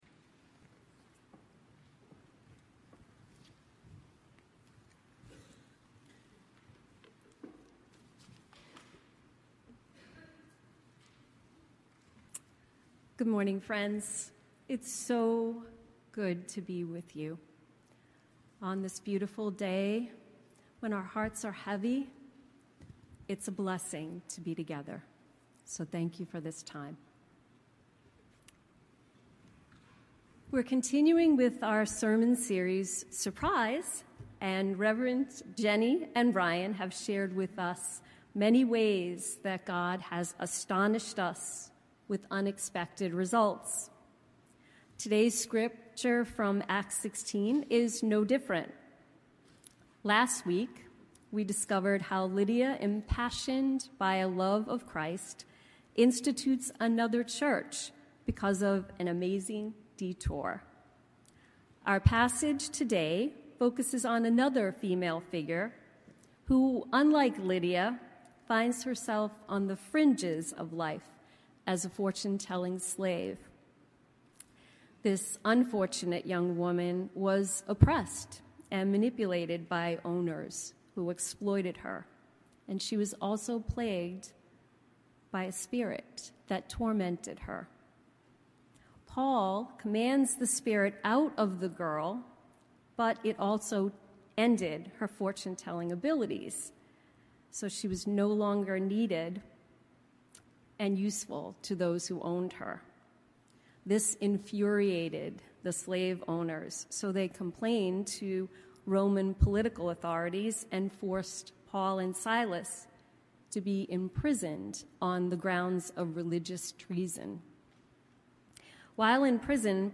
Sermons - Old South Union Church – Weymouth